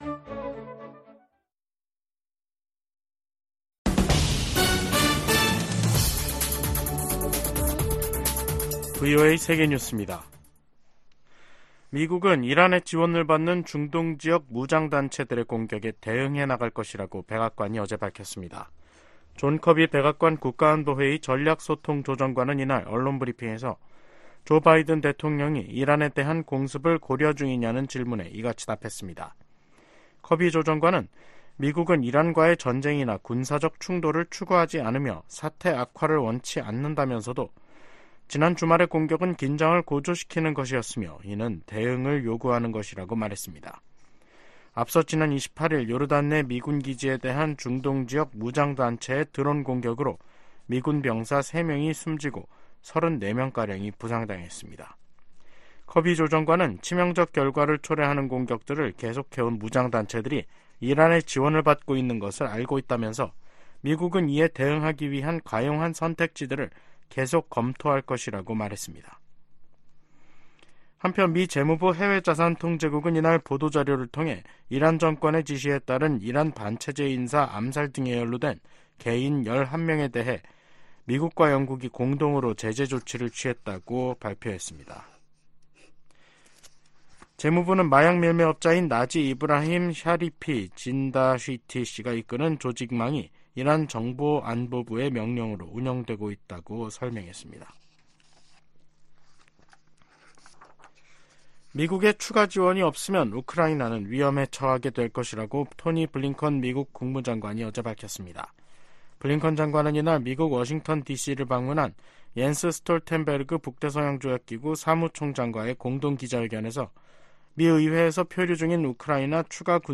VOA 한국어 간판 뉴스 프로그램 '뉴스 투데이', 2024년 1월 30일 2부 방송입니다. 북한이 이틀 만에 서해상으로 순항미사일 여러 발을 발사했습니다. 미국 국방부가 북한의 순항미사일 발사와 관련해 미한일 3국 협력의 중요성을 강조했습니다.